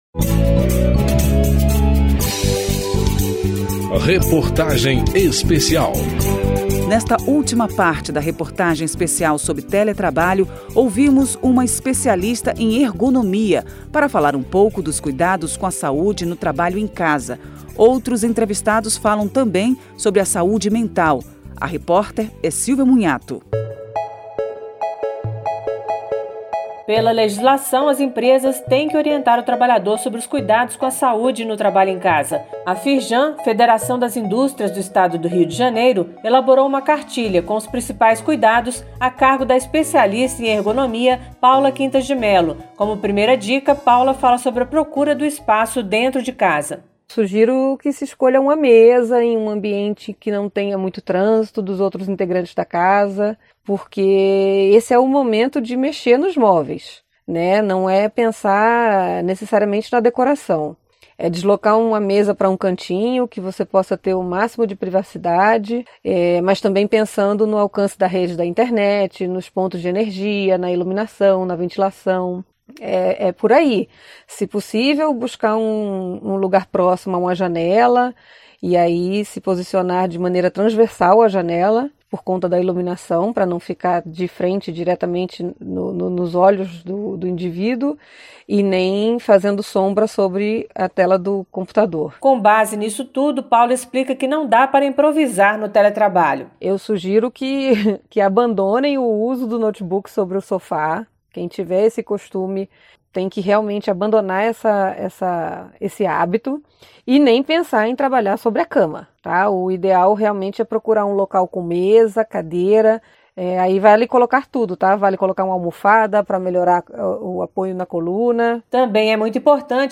Reportagem Especial
Capítulo 5: Nesta última parte da reportagem especial sobre teletrabalho, ouvimos uma especialista em ergonomia para falar um pouco dos cuidados com a saúde no trabalho em casa. Outros entrevistados falam também sobre a saúde mental.